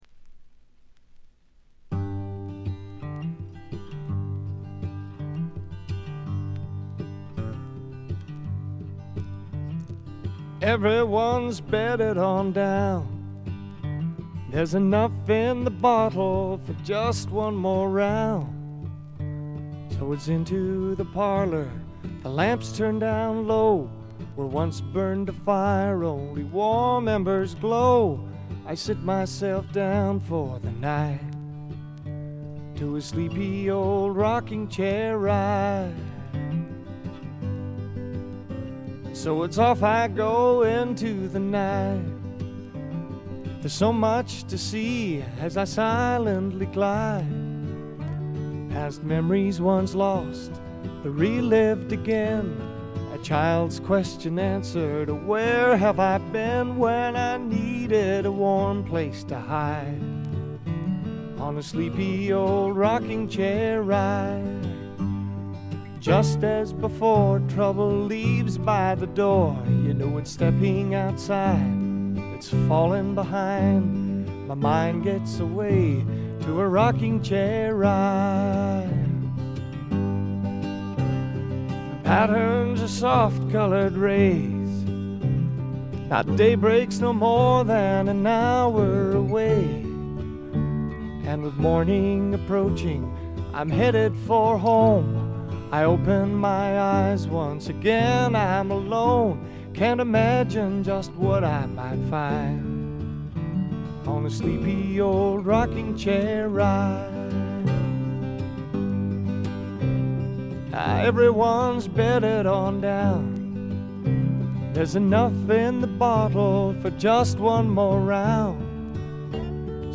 静音部で軽微なバックグラウンドノイズが少し。
乾ききった硬質感で統一された見事なスワンプロック！
試聴曲は現品からの取り込み音源です。